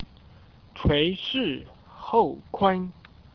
Start (High Speed Internet Only: mouse click the sentence number to hear its pronunciation in standard Chinese)